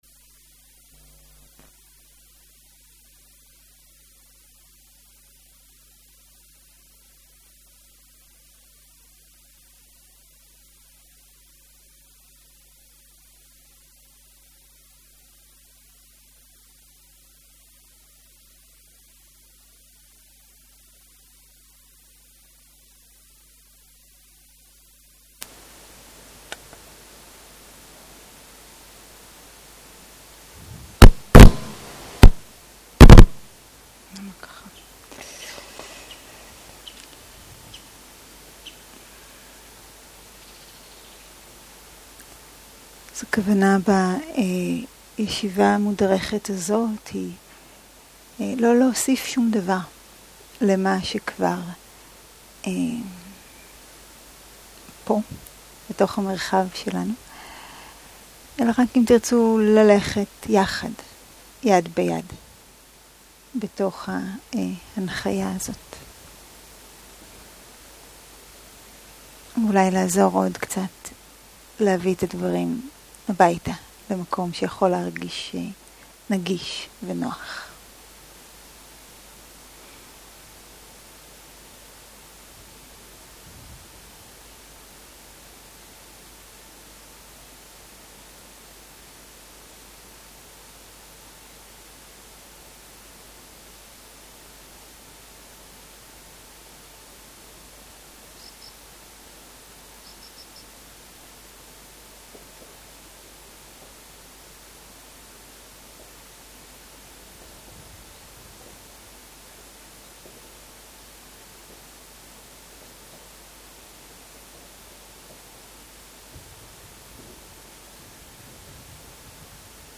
מדיטציה מונחית
Dharma type: Guided meditation שפת ההקלטה